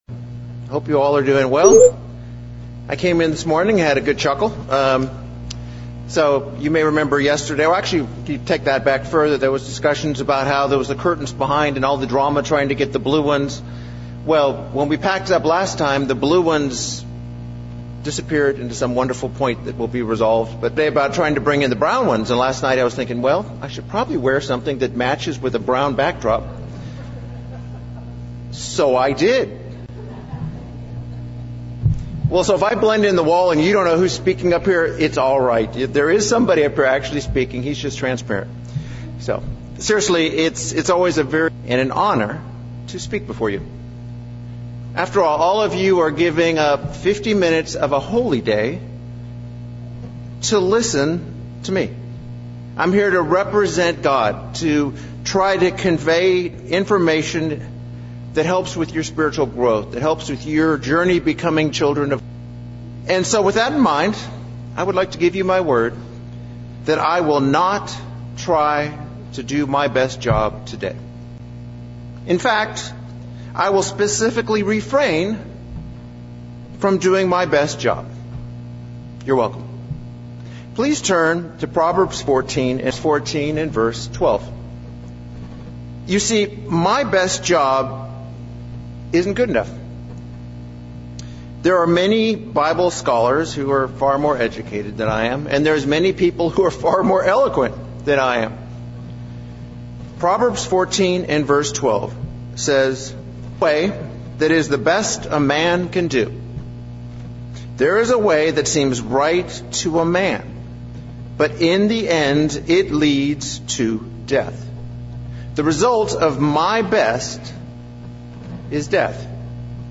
Pentecost AM sermon on what kind of Pipe are we? Are we letting God's spirit flow thru us or are we stopping up the pipe?